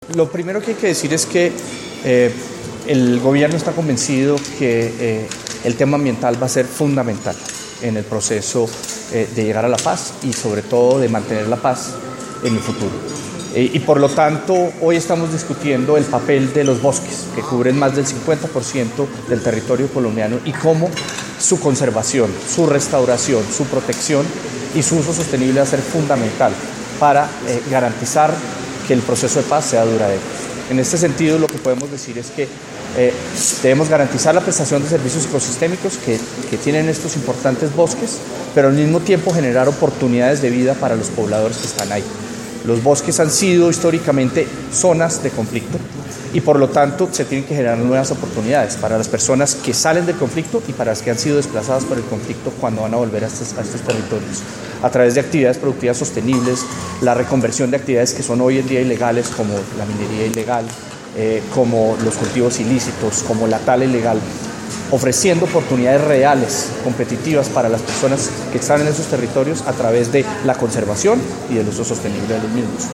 Declaraciones del Viceministro de Ambiente y Desarrollo Sostenible, Pablo Viera Samper